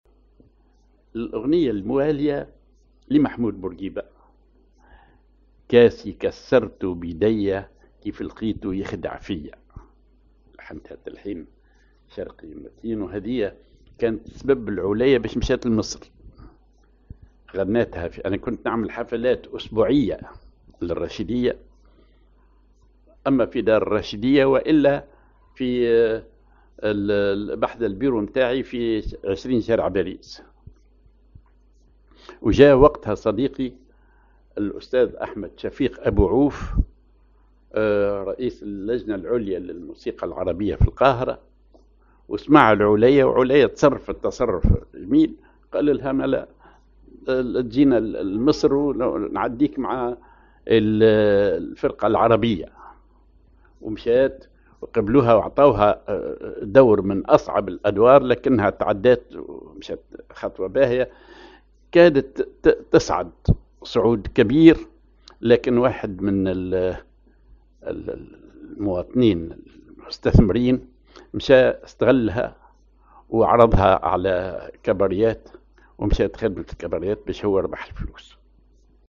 Maqam ar راحة الأرواح
genre أغنية